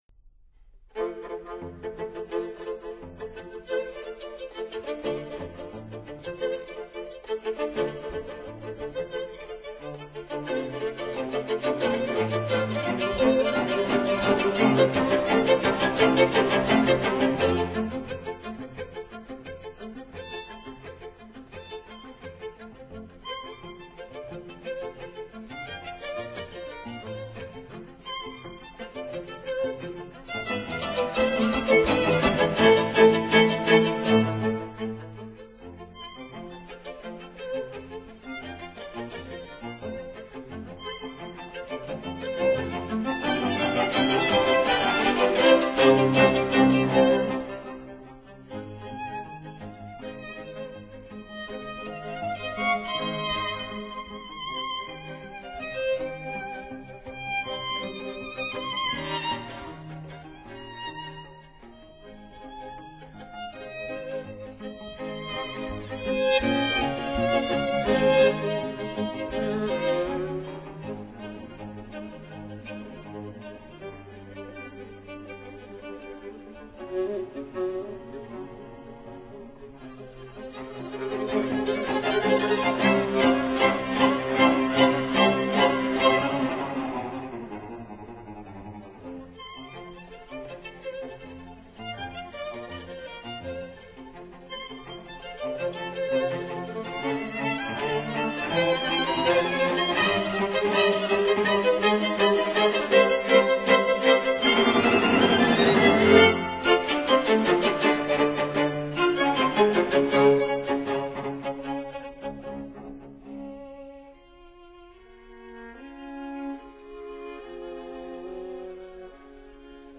violins
viola
cello